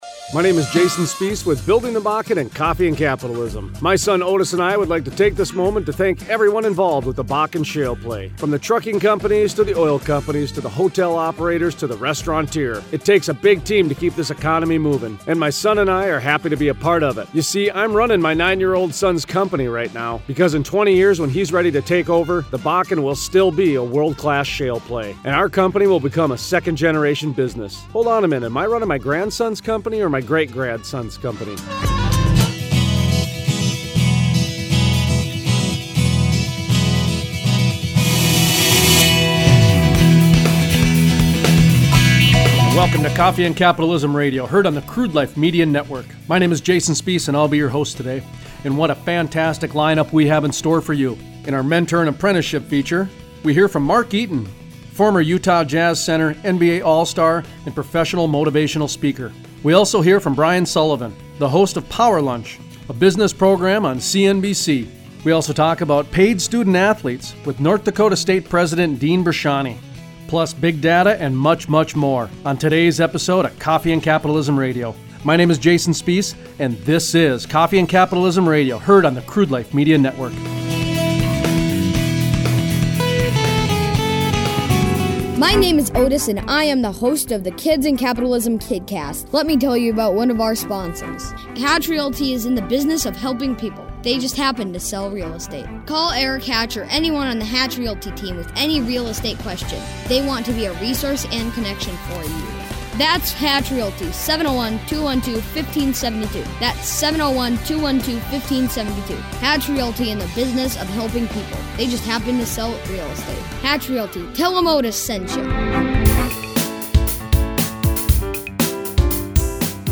Interview: US Senator John Hoeven and US Senator Heidi Heitkamp The senators talk about Grand Forks being the home for the MQ9 Reaper Wing and also extended the test UAS site for another 5 years.